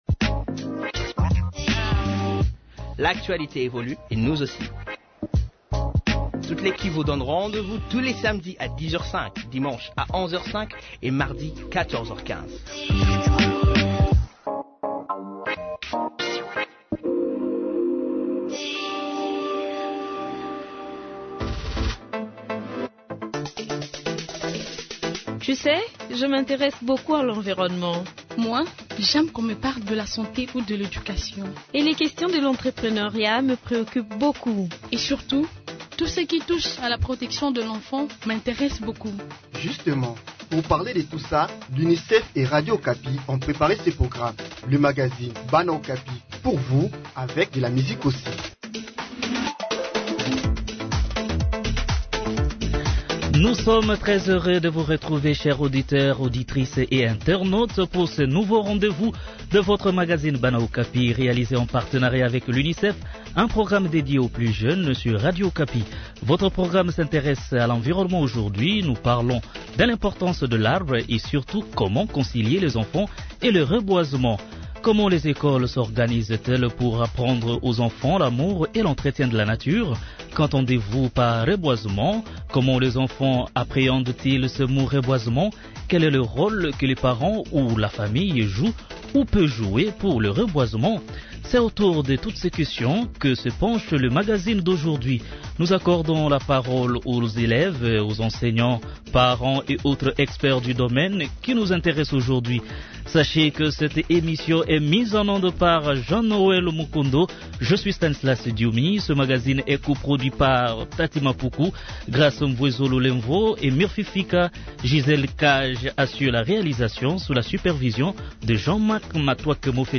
Des élèves, enseignants, parents ainsi que des spécialistes ont donné leurs avis sur le reboisement.